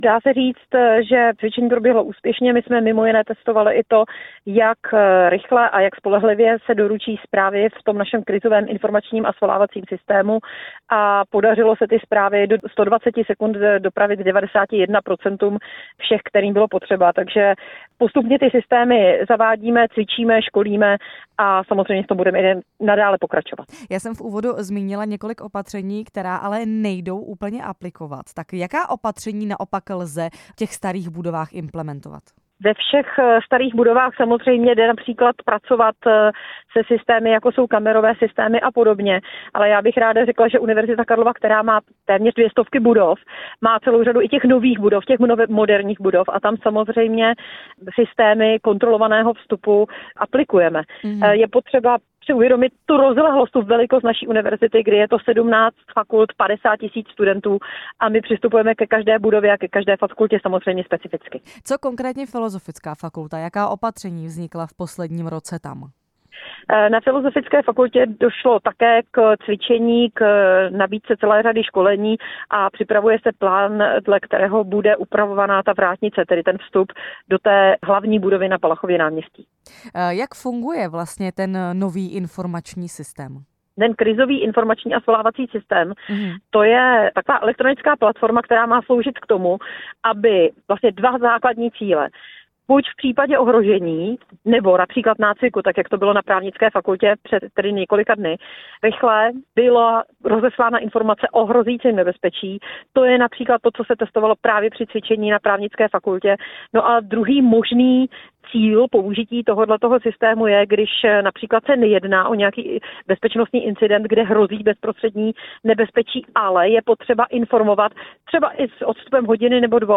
Jak proběhlo cvičení na Právnické fakultě, jaké mělo výsledky a jaké úpravy se ještě chystají? To nám ve vysílání Radia Prostor přiblížila rektorka Univerzity Karlovy Milena Králíčková.